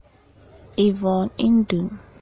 pronounciation of "Yvonne Nduom" (help·info)) wɔ wo no Kitawonsa bosome da ɛto so nkron (9) wɔ afe apem ahankron mmiensa (1953) mu wɔ Elmina a ɛwɔ mfimfini mantam wɔ Ghana.Yvonne yɛ nipa titiriw wɔ Ghana.
Tw-Yvonne_Nduom.ogg